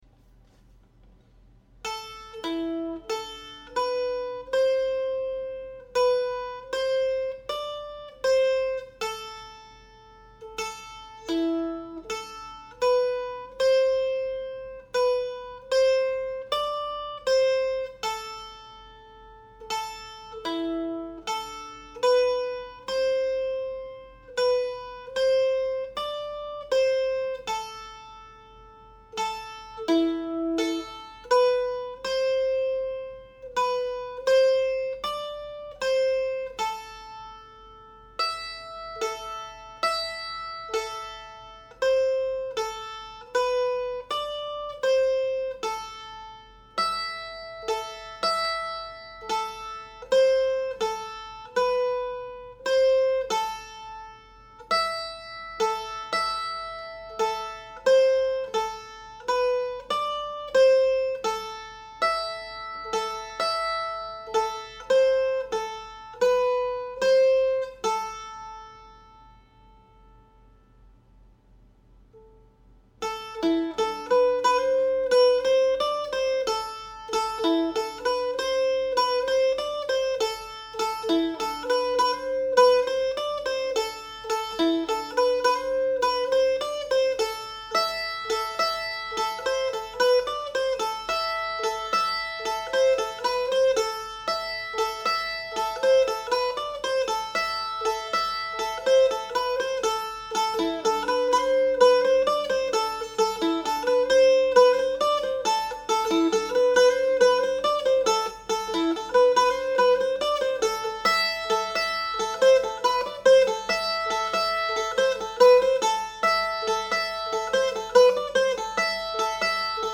Breton-Gavotte-1.mp3